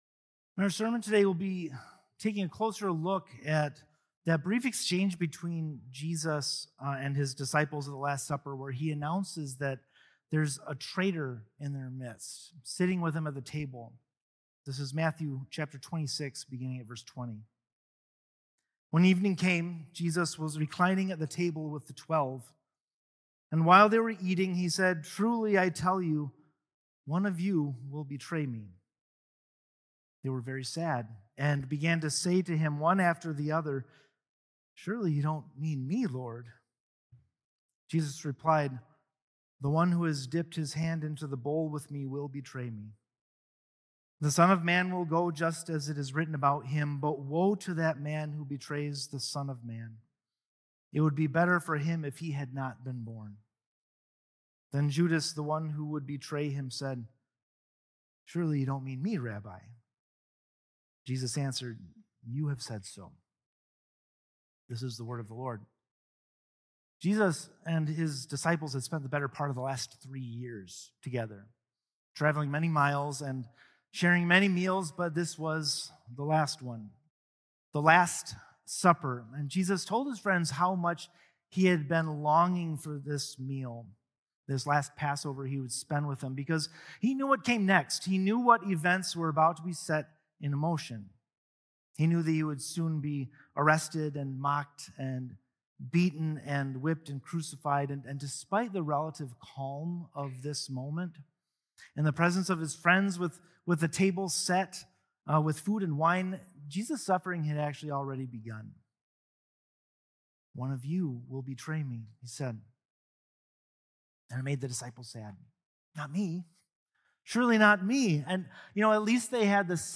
1 Emmanuel: The Word Made Flesh and Our True Home | Christmas Day Message 2024 16:44